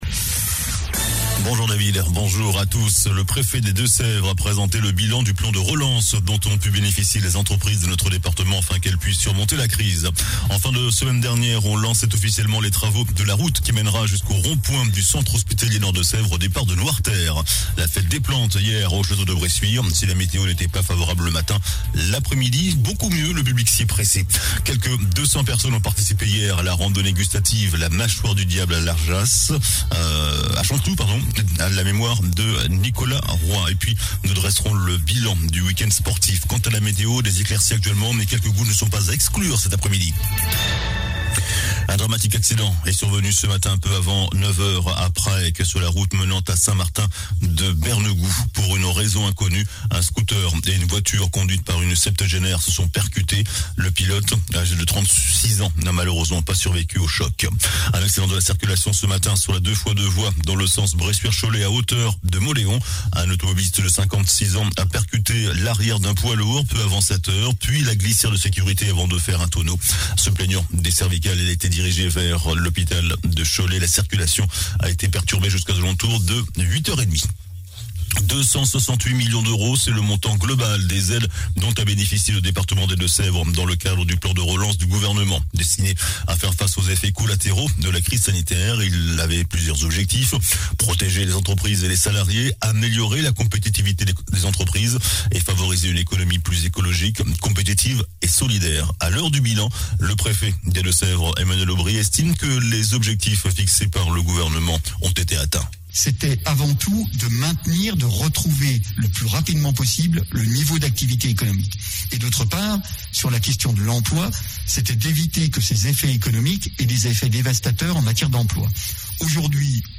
JOURNAL DU LUNDI 04 OCTOBRE ( MIDI )